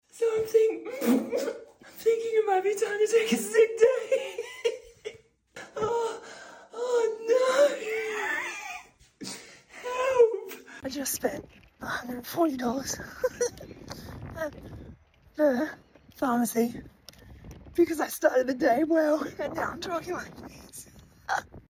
When you’re speaking on a educational panel in 24 hours and currently sound like you live inside a helium balloon…